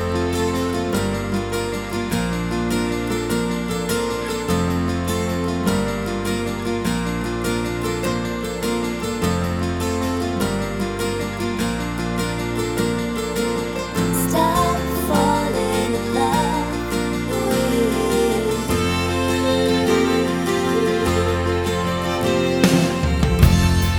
Irish